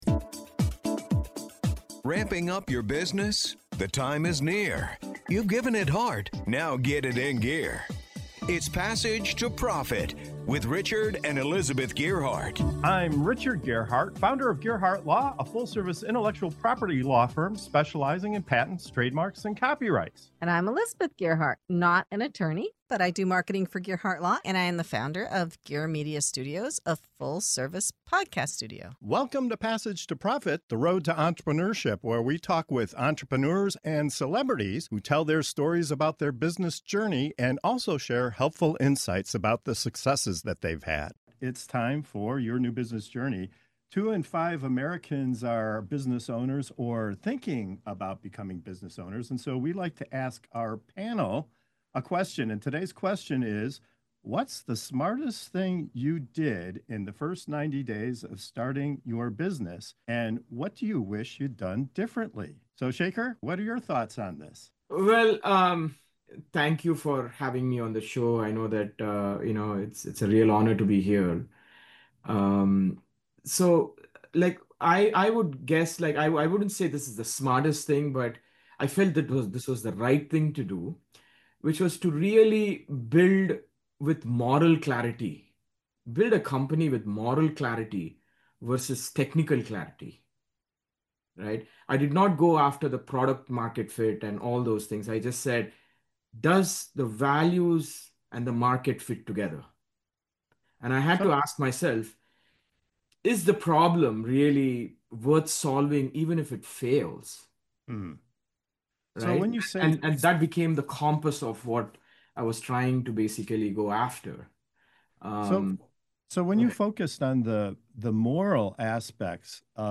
This episode dives into the pivotal first 90 days of starting a business, as our panel of founders shares the bold moves, hard lessons, and surprising insights that shaped their journeys.